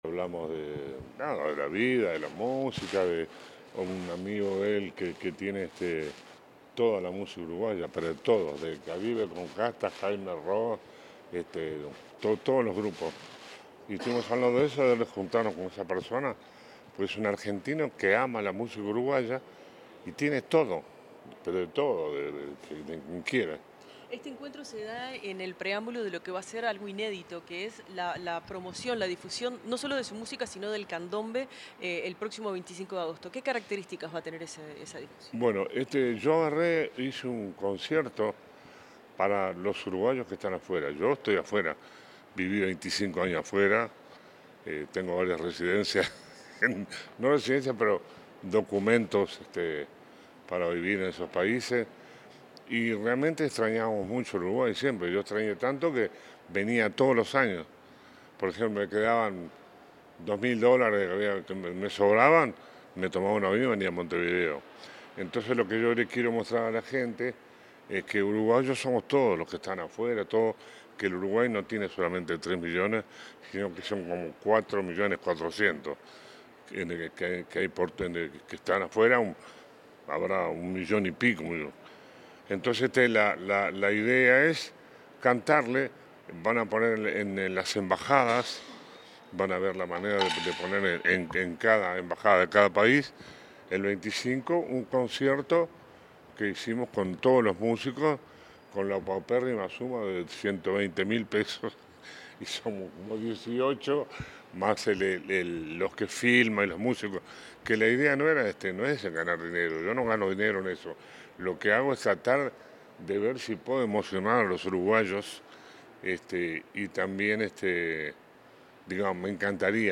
Declaraciones del músico Ruben Rada
Declaraciones del músico Ruben Rada 14/08/2025 Compartir Facebook X Copiar enlace WhatsApp LinkedIn Tras una reunión con el presidente de la República, Yamandú Orsi, en la Torre Ejecutiva, el músico uruguayo Ruben Rada diálogo con los medios de prensa.